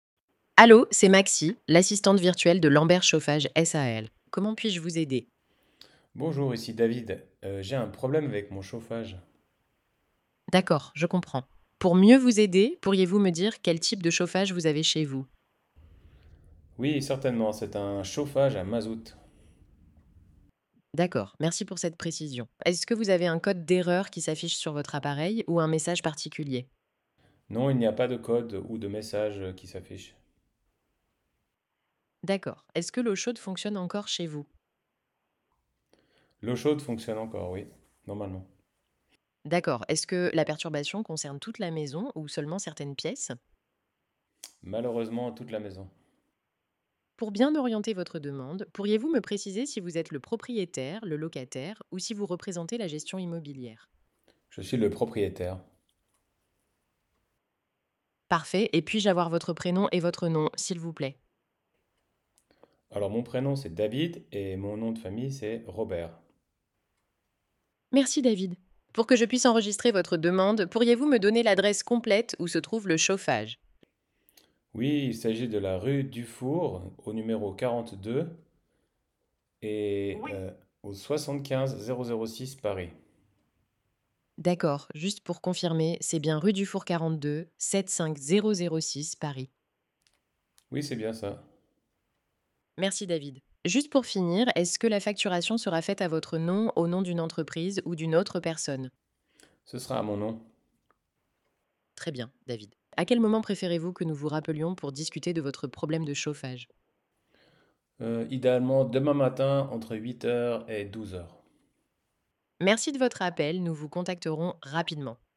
Conversation type avec Allo Maxi